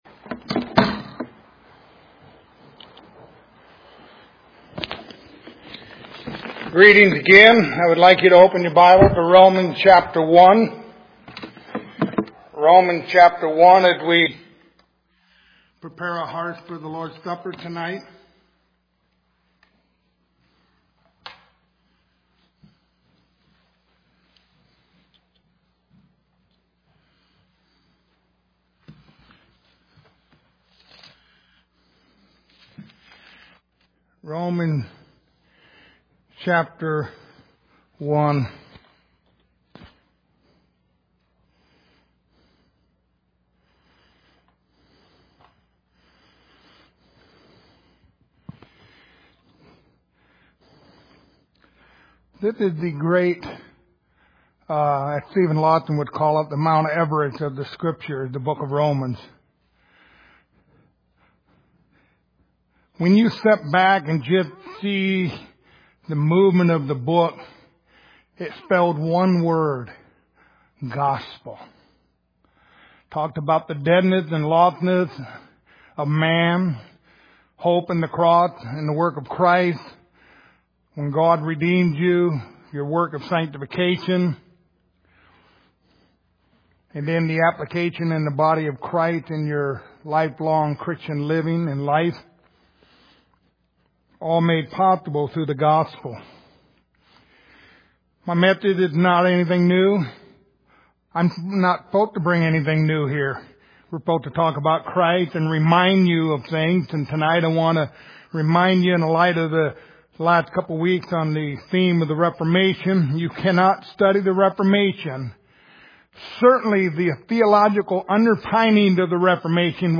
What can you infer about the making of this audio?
Passage: Romans 1:1-32 Service Type: Sunday Evening